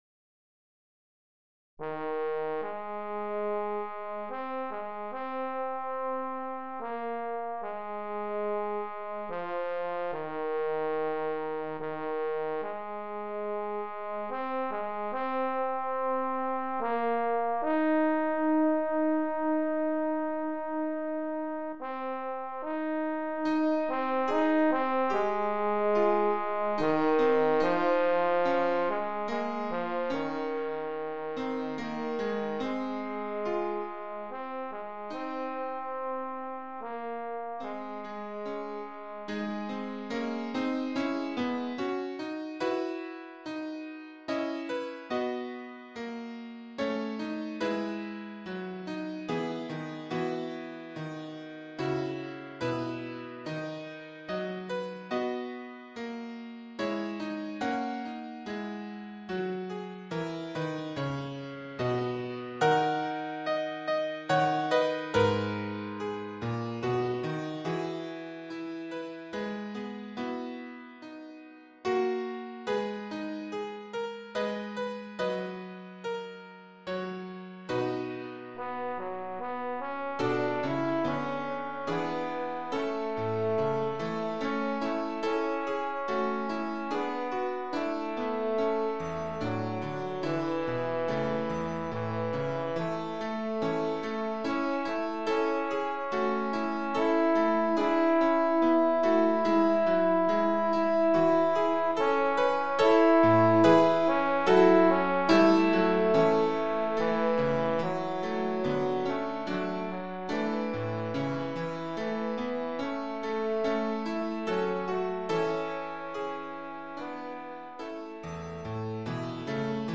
Voicing: Trombone Solo